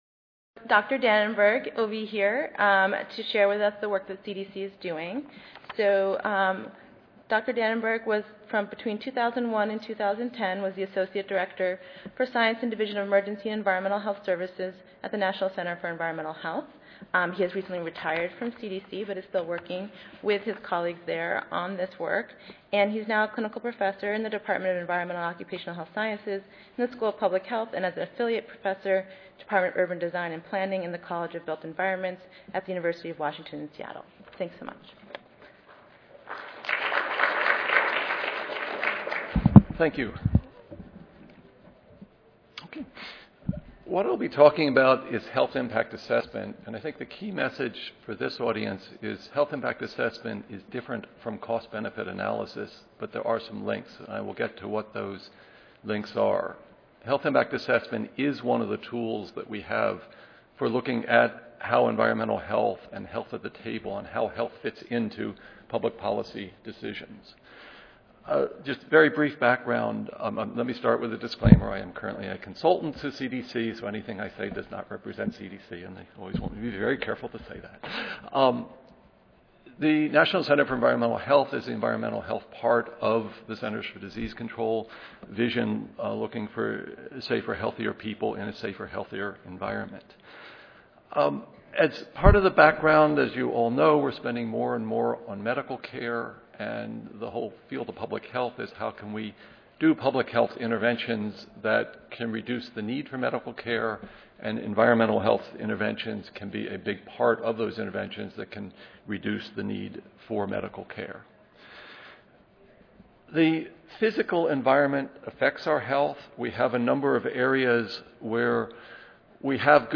The National Prevention Strategy says that HIA can help achieve the key strategy of building healthy and safe community environments. In this talk, I will outline some key components of HIA and discuss specific examples from CDC.